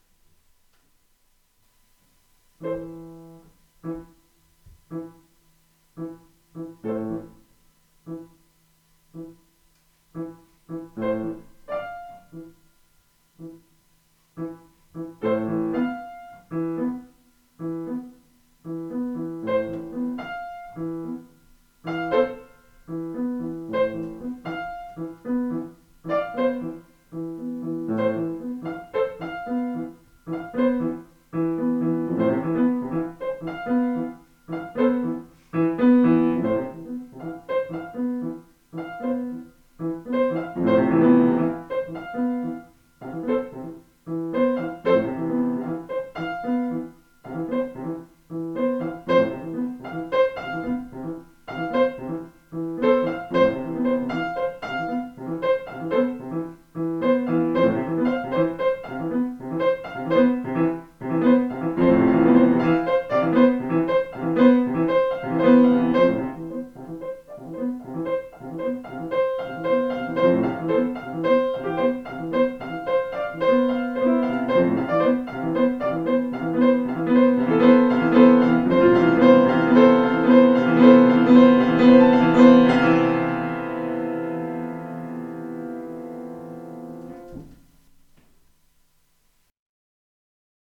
Sonderless – 3 piano miniatures